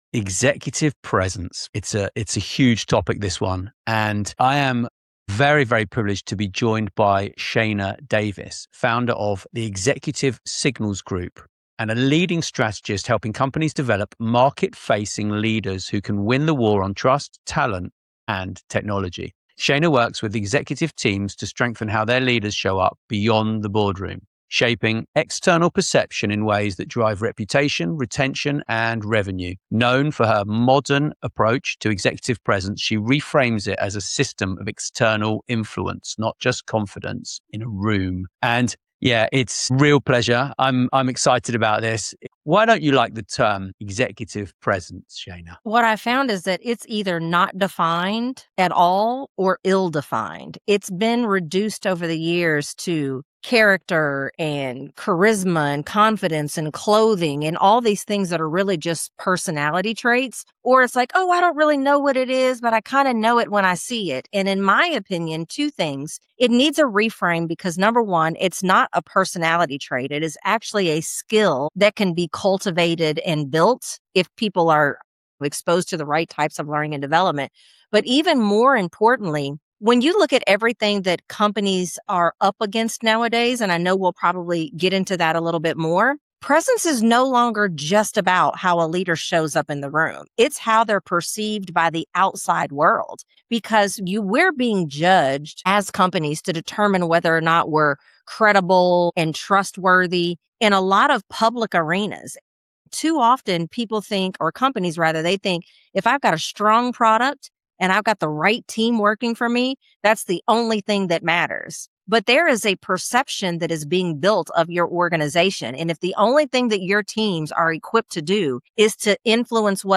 GM's Cruise, Babylon Health, IBM Watson failures reveal why human-centered transformation wins. Expert roundtable with billion-dollar insights.